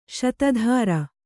♪ śata dhāra